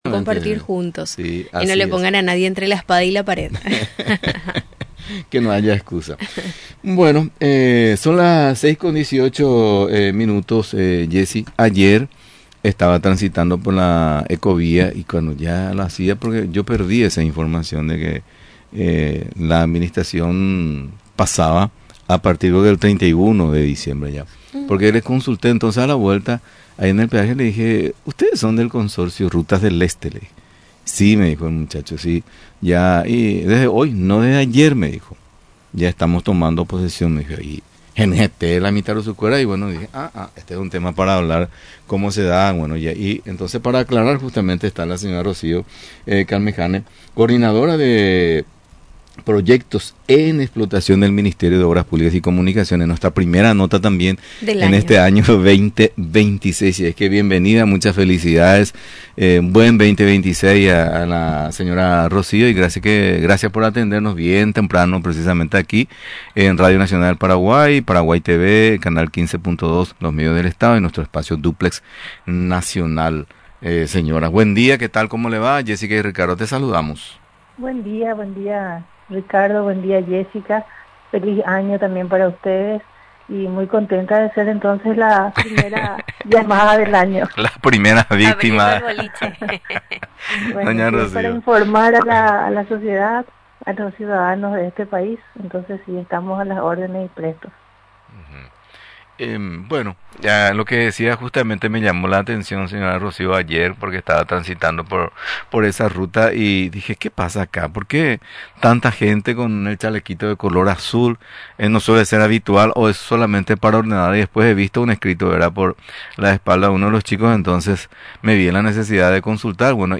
Durante la entrevista en Radio Nacional del Paraguay, mencionó que ésta decisión no implicará la suba en la tarifa.